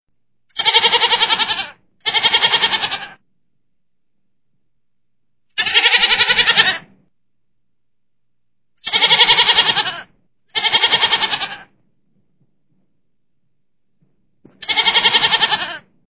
Goat Ringtone
goat.mp3